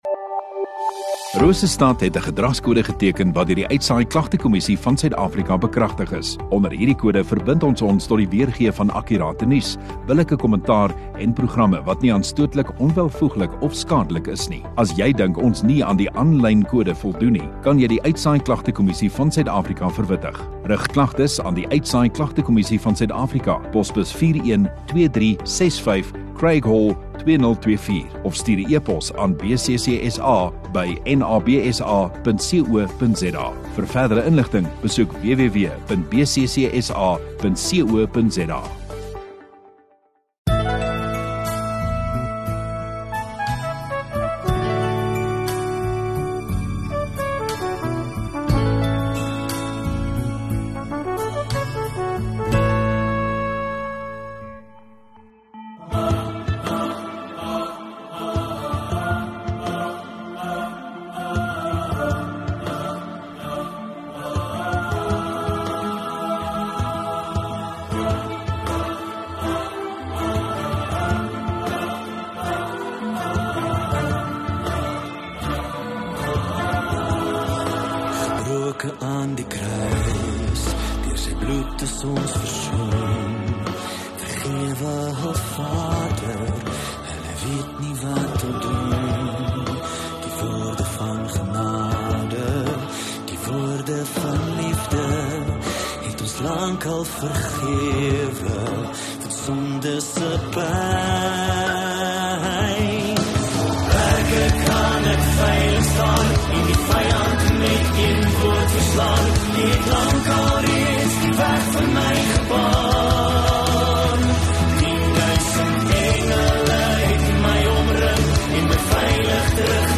22 Sep Vrydag Oggenddiens